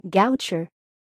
Goucher College (/ˈɡər/
GOW-chər) is a private liberal arts college in Towson, Maryland, United States.
En-US-Goucher.ogg.mp3